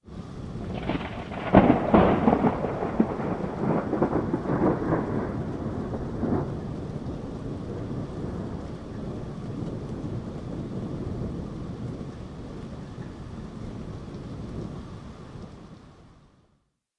WEATHE R的现场记录" 00196雷霆14关闭
描述：靠近约。1公里的雷声，不长的共振和微弱的雨声，由变焦H2的后部
Tag: 照明 单触发 风暴